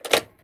gear_rattle_weap_launcher_05.ogg